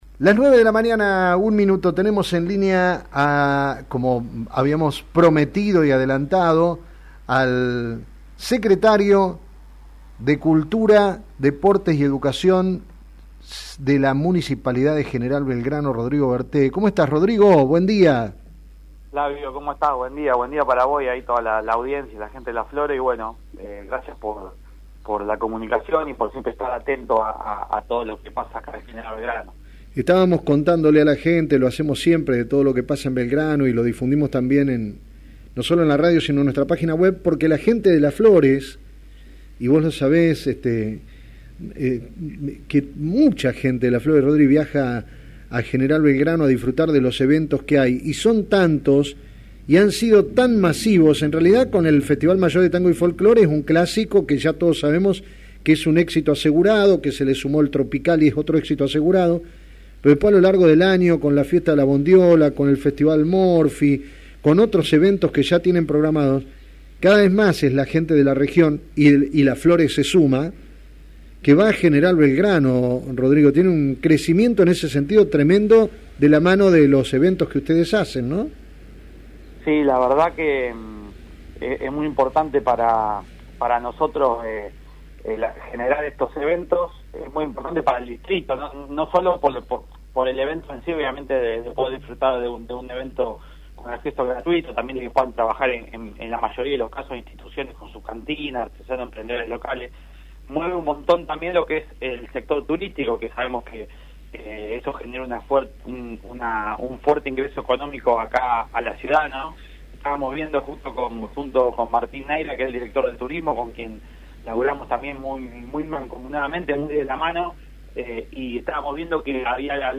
contó a Play Radios el Secretario de Cultura, Deportes y Educación Rodrigo Berte.